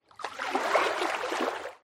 Minecraft Dolphin Swim 2 Sound Effect Free Download